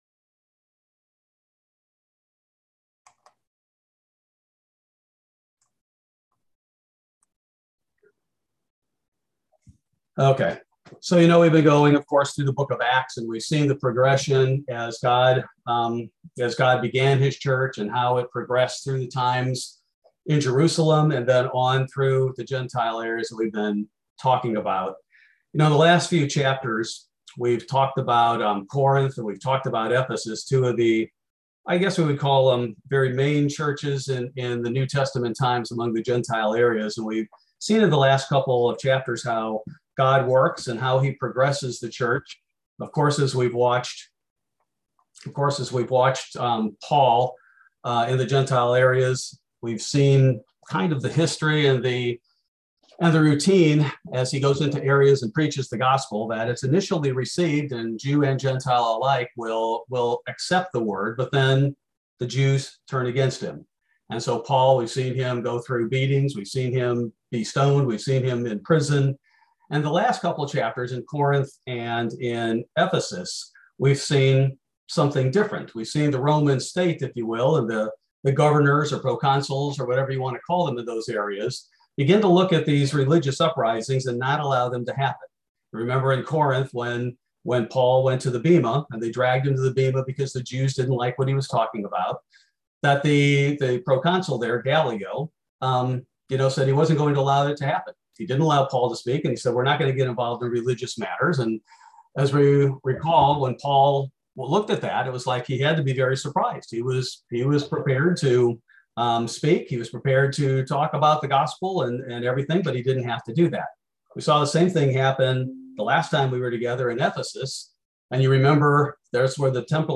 Bible Study: December 15, 2021